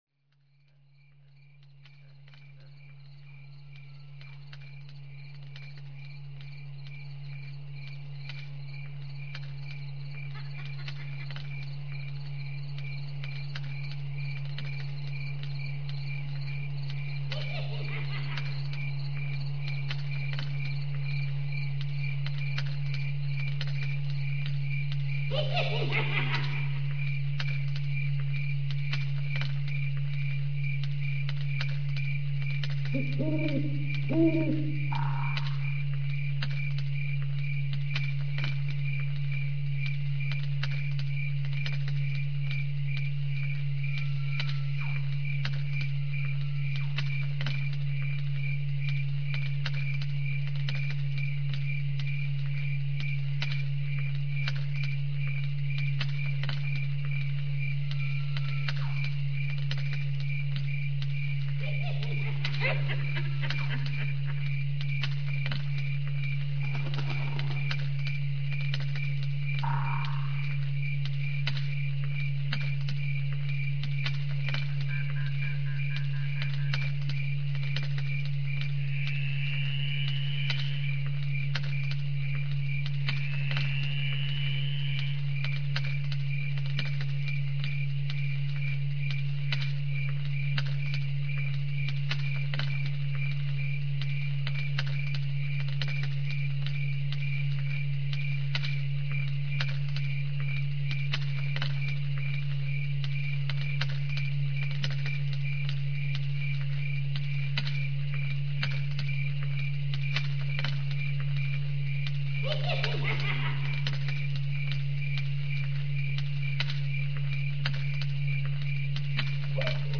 HALLOWEEN FOREST SOUNDS BINAURAL BEATS MP3 MEDITATION - Tradebit
64kbps-full-moon-forest-delta.mp3